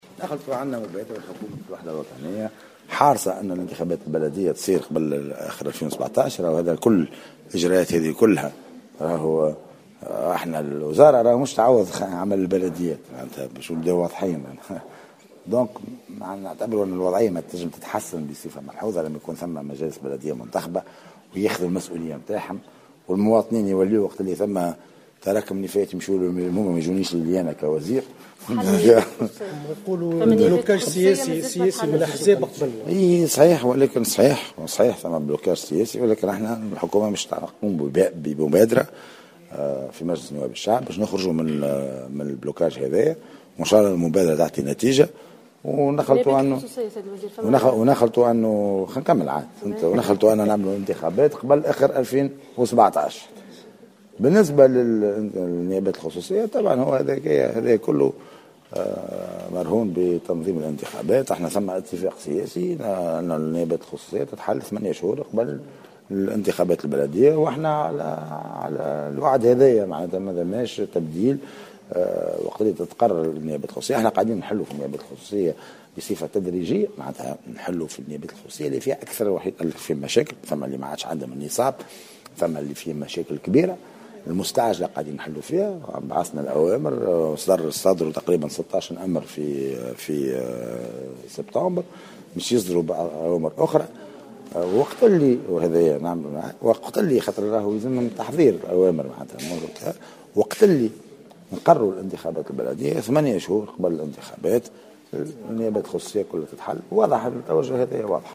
وأفاد المؤخر خلال ندوة صحفية اليوم الأربعاء بقصر الحكومة بالقصبة ان الحكومة سوف تنتظر انتهاء المصادقة على مشروع الميزانية لعام 2017 وعرض مبادرتها الجديدة لحلحلة الإشكال الحاصل في القانون الانتخابي للبلديات.